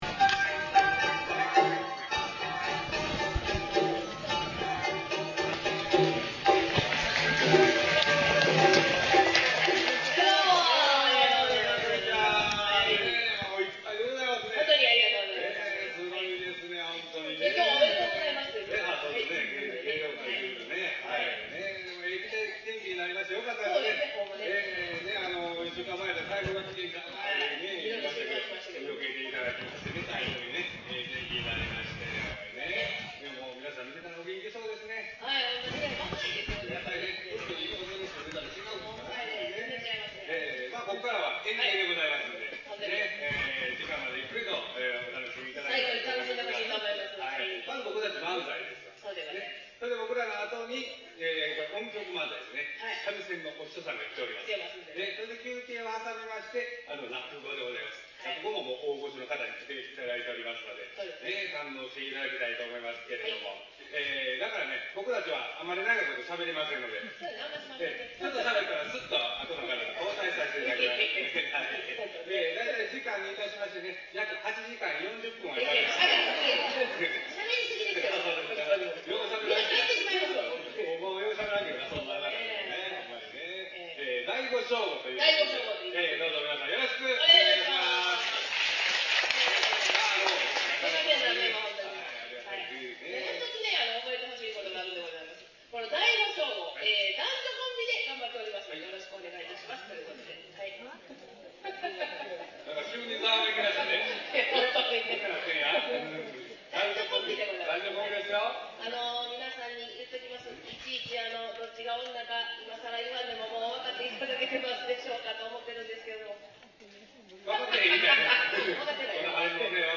敬老観劇会
演芸は漫才、三味線漫才、落語であった。
漫才、三味線漫才は軽妙な語りと三味線によりによりあっという時間の経過があった。落語のは古典落語であって、間抜け者が新築の普請を褒めるもおであり かえって新築者の不興を買う物語である。